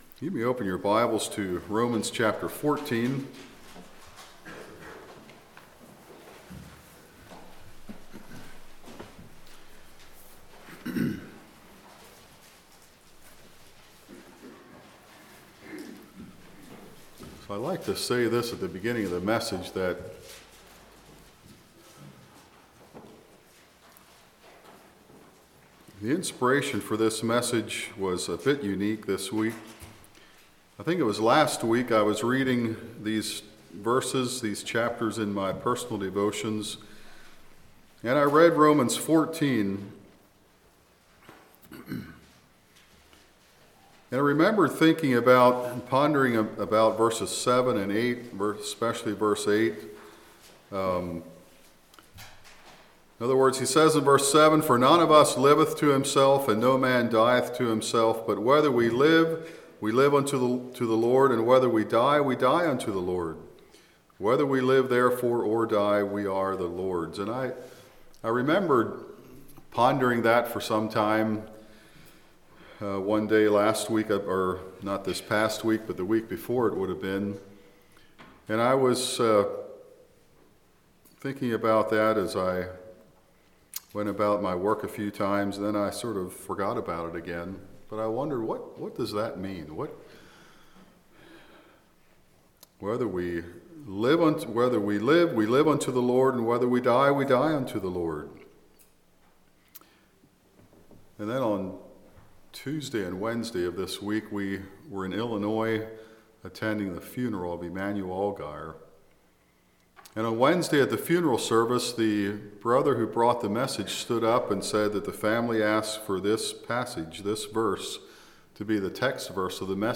11.26.23 Play Now Download to Device We Are the Lord's Congregation: Chapel Speaker